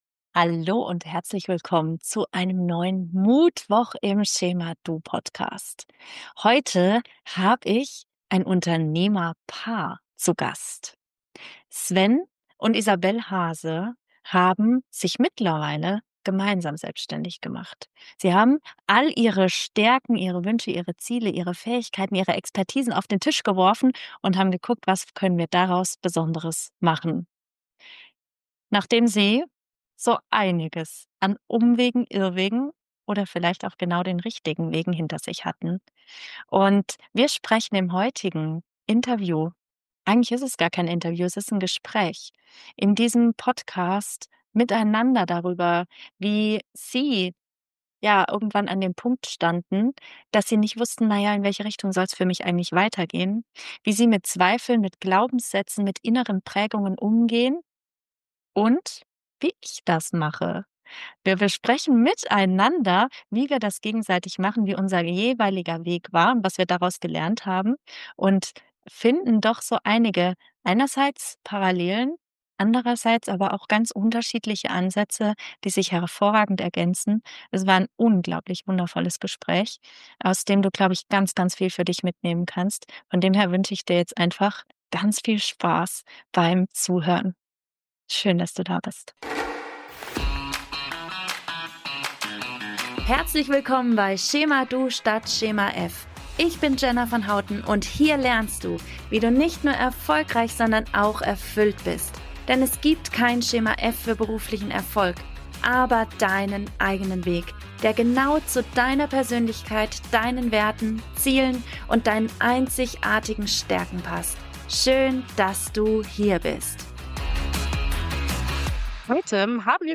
Wir beleuchten gemeinsam, wie Kopf, Herz und Körper zusammenfinden müssen, damit Erfolg nicht nur eine Zahl auf dem Konto ist, sondern sich auch richtig gut anfühlt. Erfahre in diesem inspirierenden Gespräch, wie du deine ‚Zone of Genius‘ findest und warum Veränderung manchmal viel leichter gehen darf, als wir uns das im stillen Kämmerlein ausmalen.